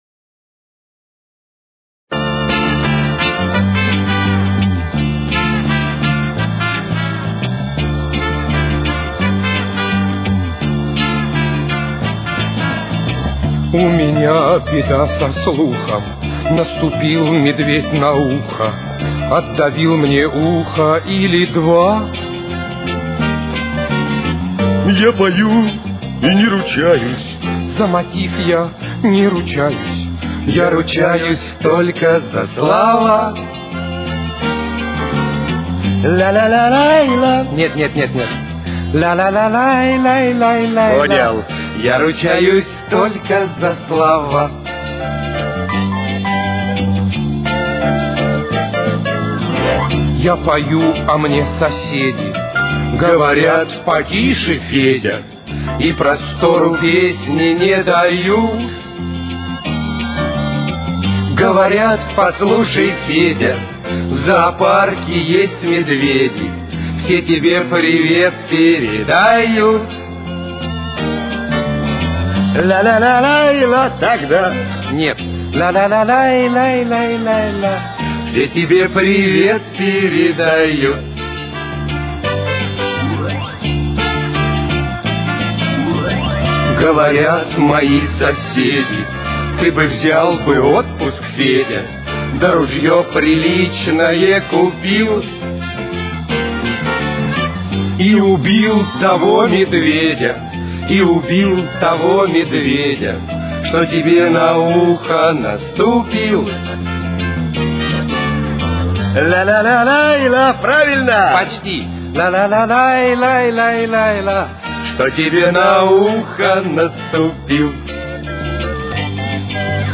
Темп: 88.